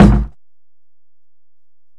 Kick (15).wav